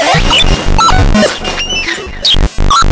雷盖风琴
描述：8小节的哈蒙德风琴以雷鬼的方式在后拍上演奏。 隐含的和声。 Am7Dm7Am7Em7
标签： 125 bpm Reggae Loops Organ Loops 2.59 MB wav Key : A
声道立体声